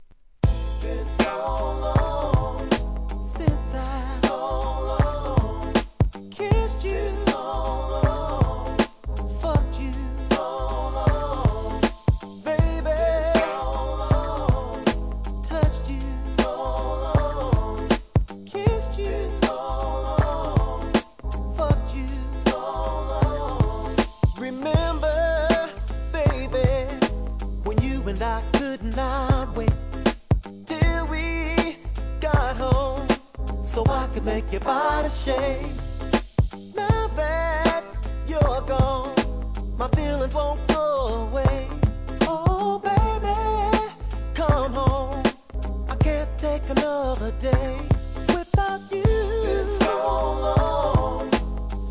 HIP HOP/R&B
ファルセット・ヴォーカルが素晴らしい!